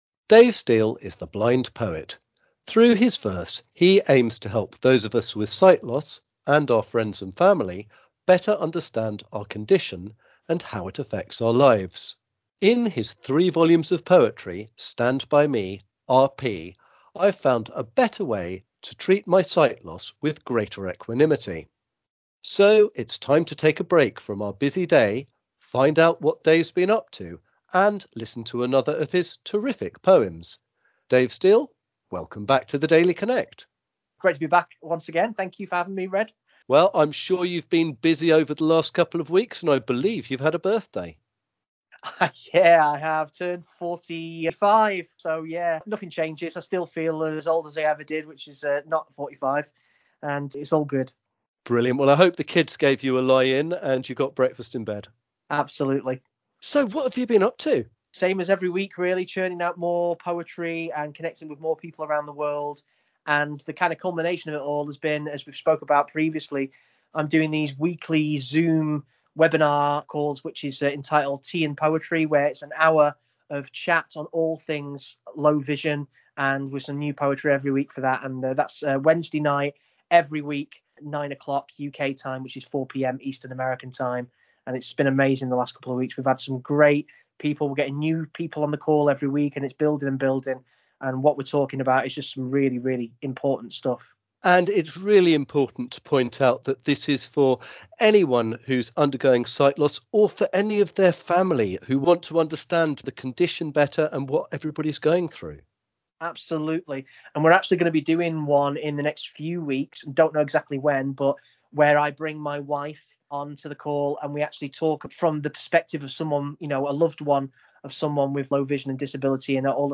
He performs the poem for the first time and exclusively on RNIB Connect Radio.